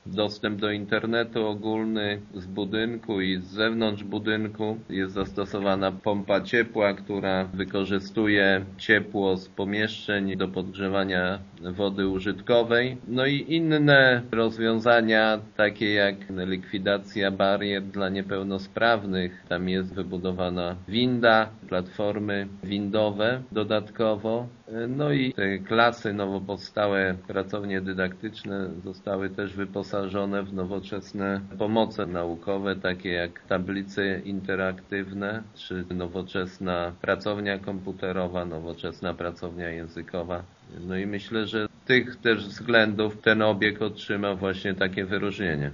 Wójt Jacek Anasiewicz, który odebrał wyróżnienie przyznaje, że gmina starała się zrealizować nowoczesną inwestycję i zastosować innowacyjne rozwiązania: